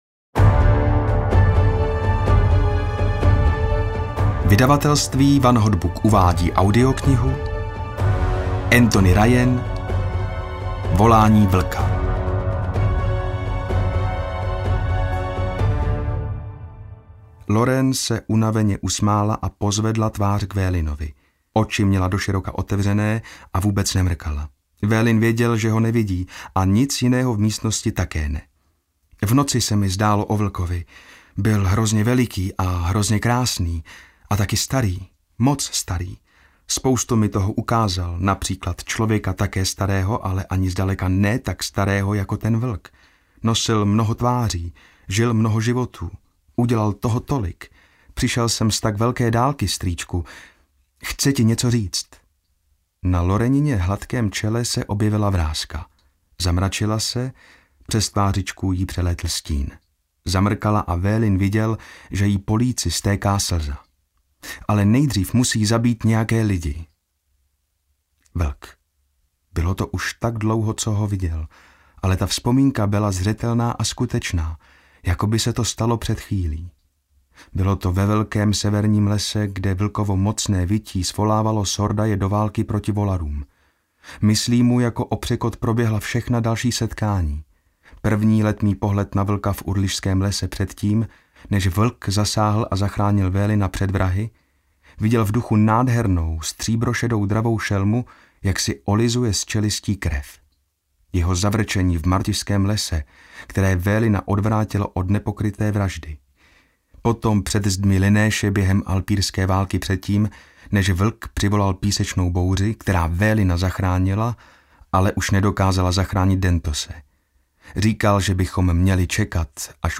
Volání vlka audiokniha
Ukázka z knihy